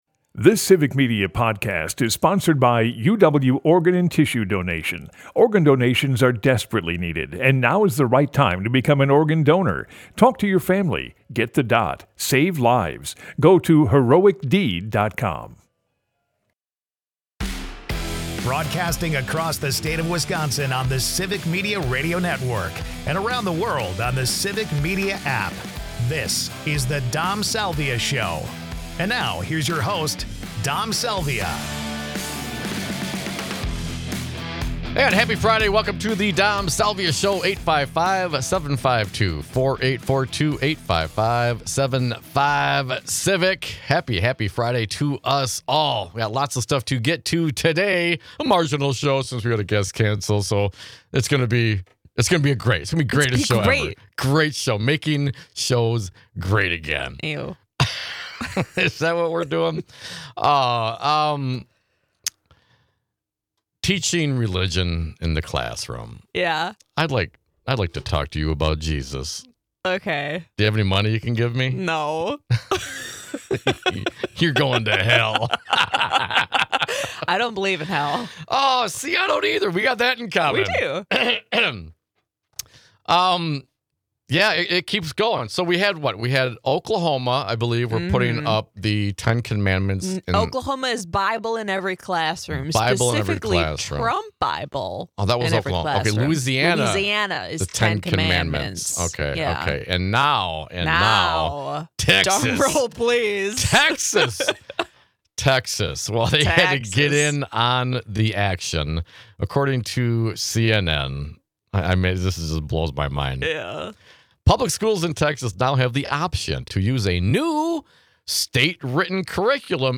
Listen throughout the state of Wisconsin on the Civic Media network and worldwide on the Civic Media app.